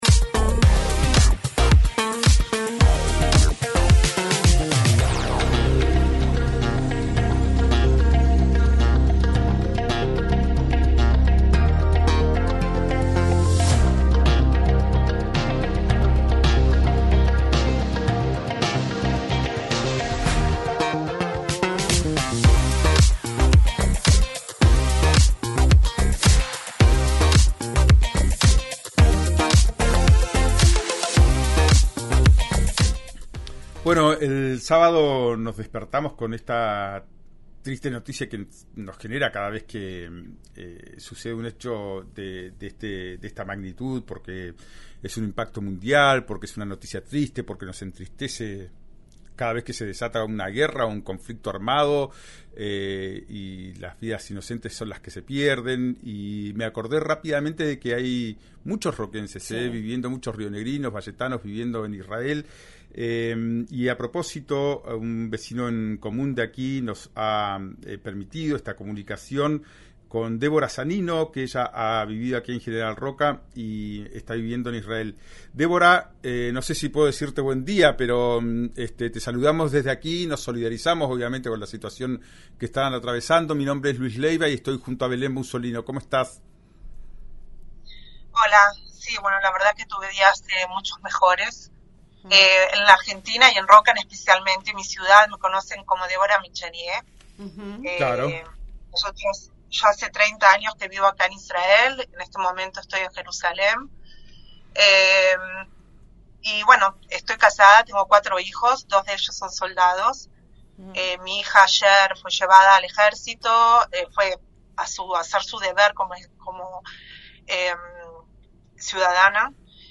'No sabemos si cayó algo (bomba) o si logramos interceptarlo en el aire', explicó mientras se escuchaban estallidos detrás de la entrevista.
En el momento en el que sonó la alarma, desde «Ya es Tiempo», le ofrecieron interrumpir la entrevista para que pueda atender la situación, pero ella prefirió continuar, resaltando que quería que se conociera lo que estaba pasando.